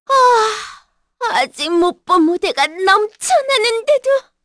Shamilla-Vox_Dead_kr.wav